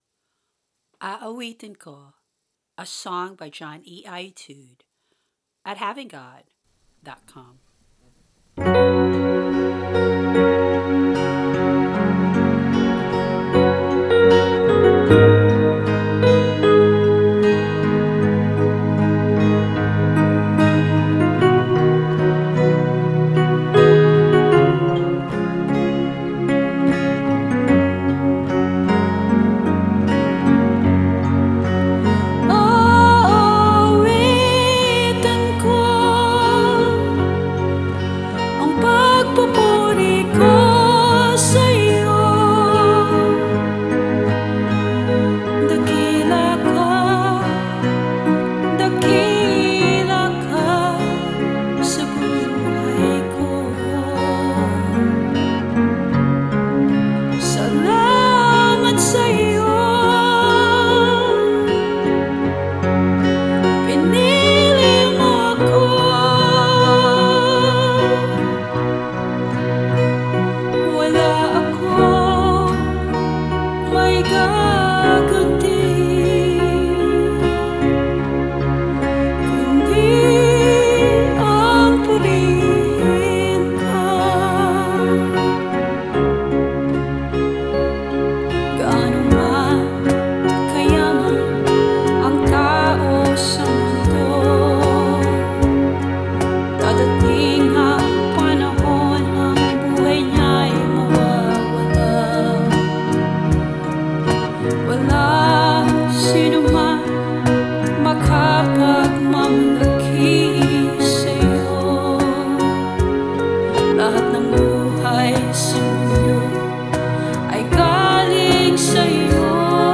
Vocals, guitar, bass and drum machine
Strings and keyboard